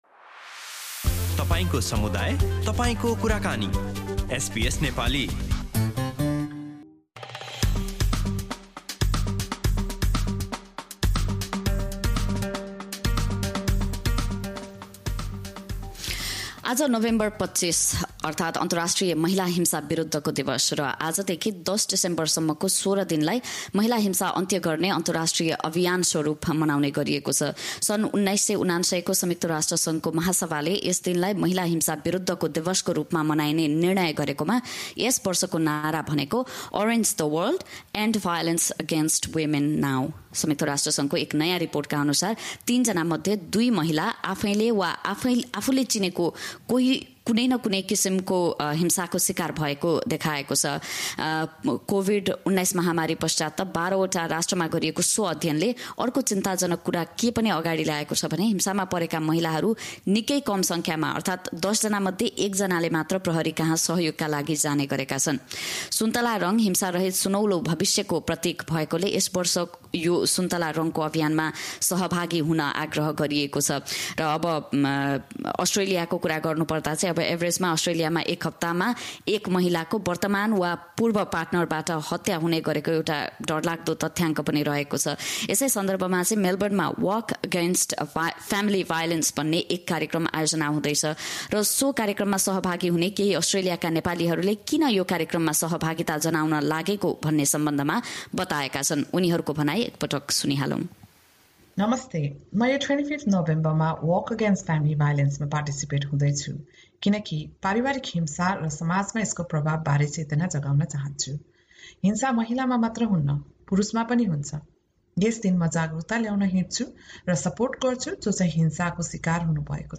Every year, November 25 to the December 10 is celebrated as 16 Days Activism Against Gender Based Violence. This year, some Nepali women in Melbourne took part in the 'Walk Against Family Violence' program. Click on the media player icon above to listen to what they have to say about the importance of this annual program.